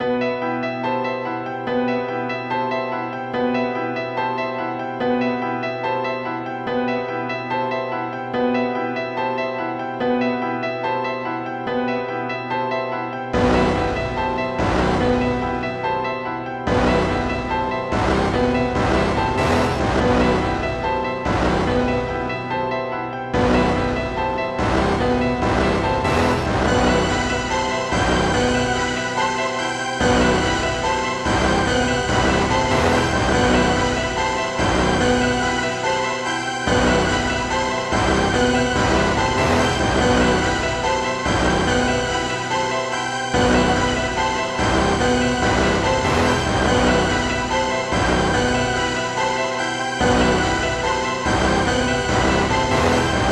SOUTHSIDE_melody_loop_fences_144_Em.wav